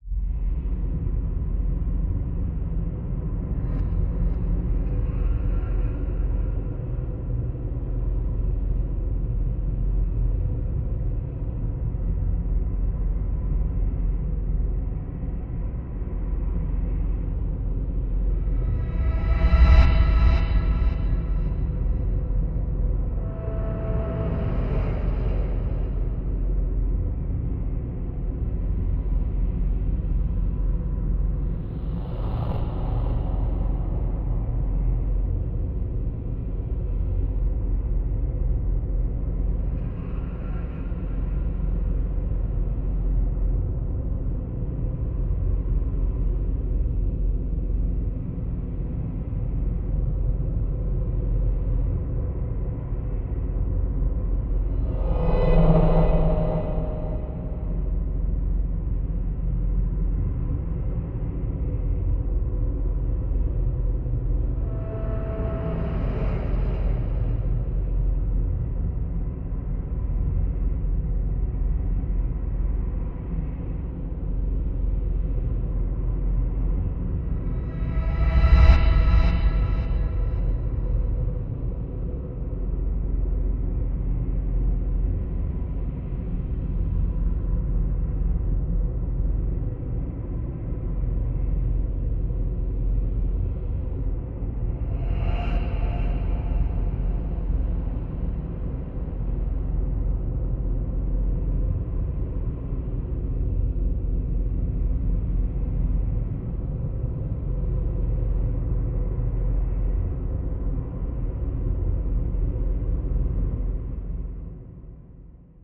free horror ambience 2
ha-waterheater.wav